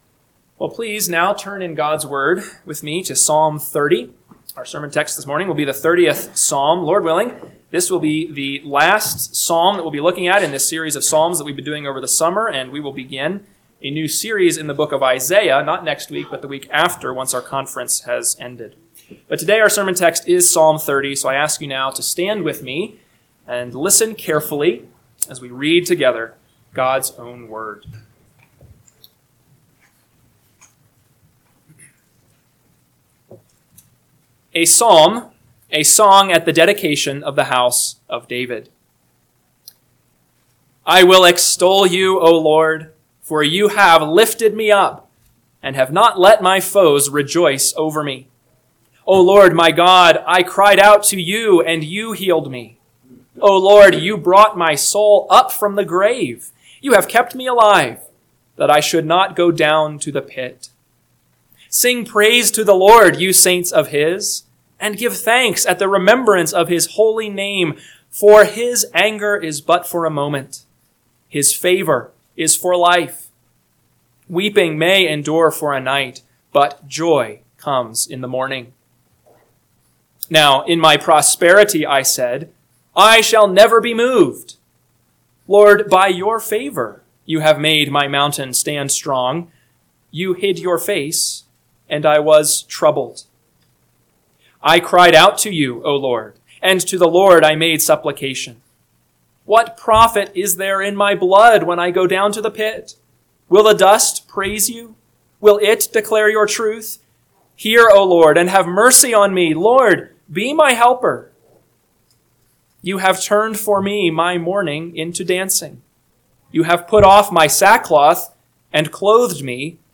AM Sermon – 9/14/2025 – Psalm 30 – Northwoods Sermons